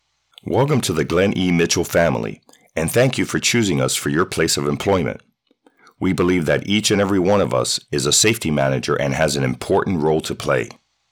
From warm to authoritative, I bring the right tone every time—tailored to connect with your audience.